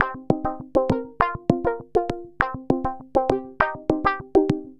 tx_synth_100_filttaps_CMin.wav